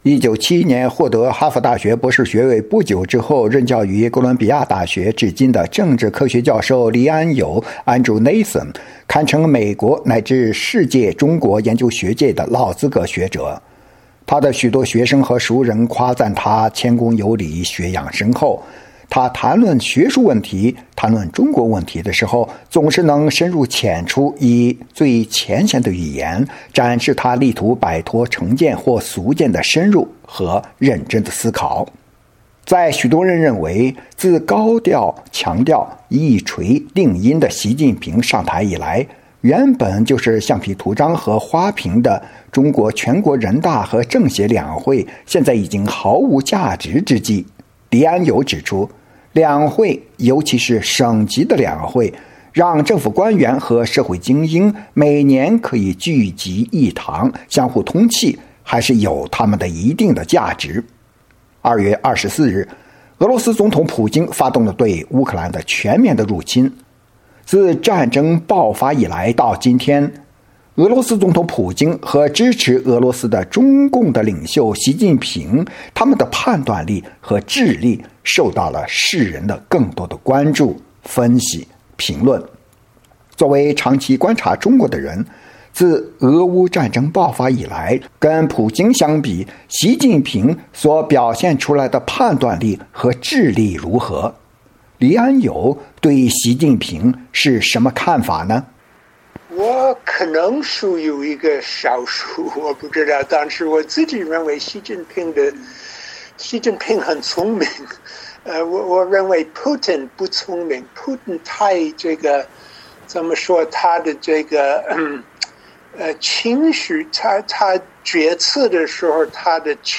专访黎安友（1）：谈普京和习近平的比较